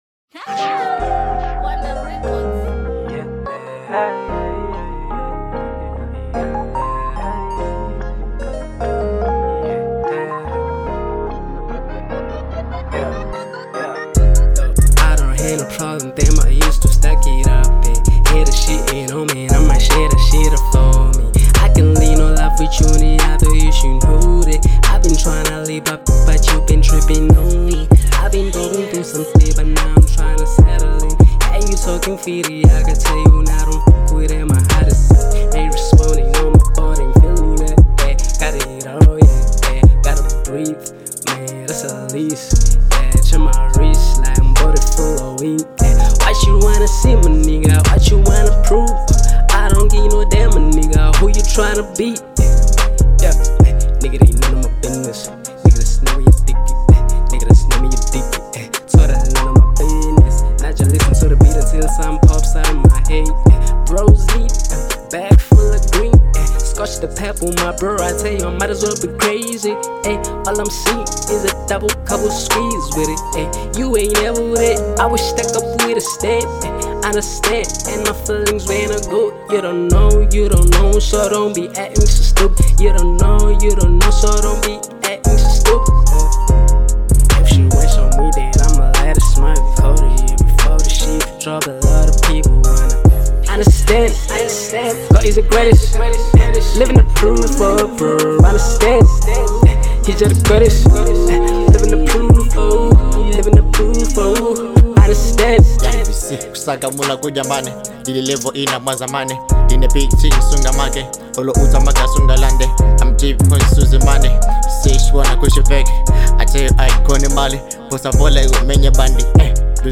South African hip hop/rap artist